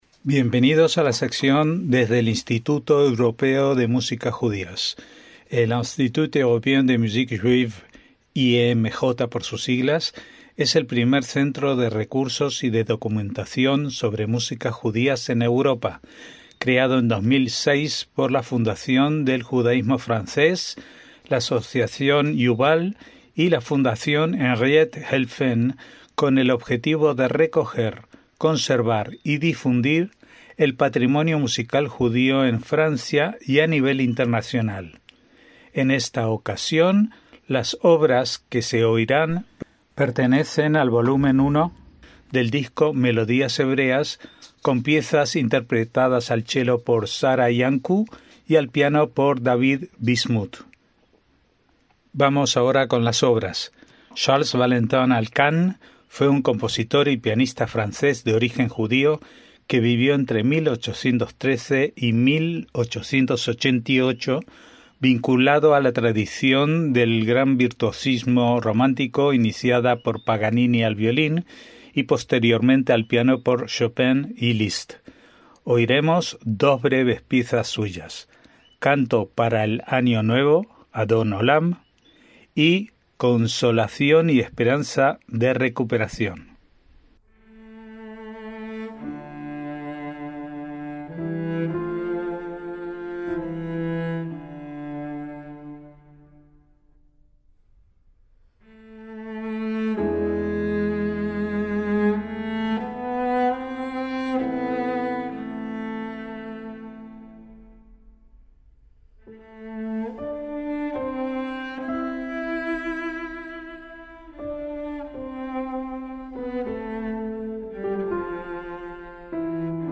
chelo
piano